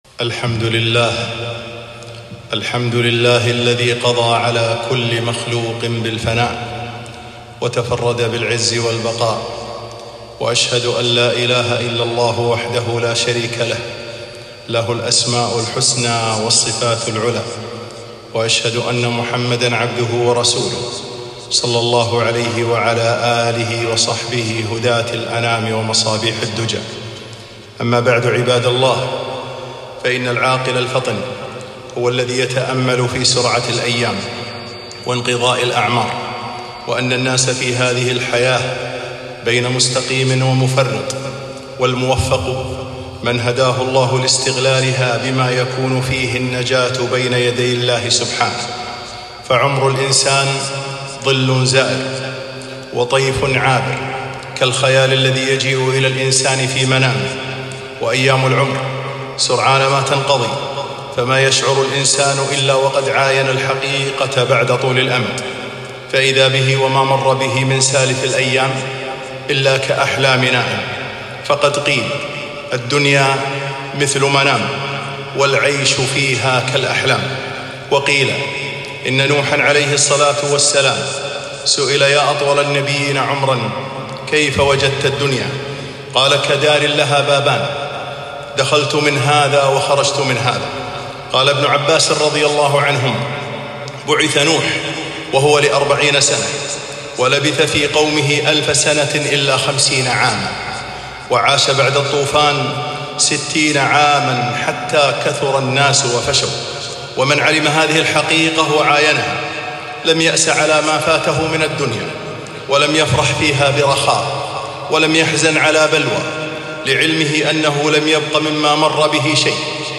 خطبة - موعظة وذكرى
خطبة الجمعة ألقيت بمسجد الشعبي بتاريخ ٣-٣-٢٠٢٣م